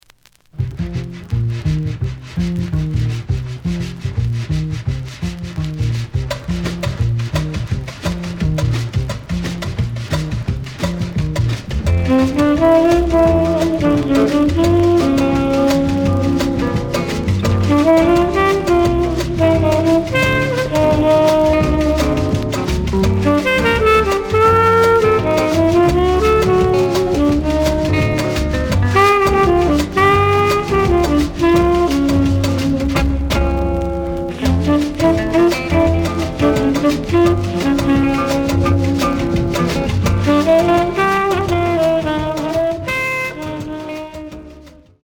The audio sample is recorded from the actual item.
●Genre: Modern Jazz, Cool Jazz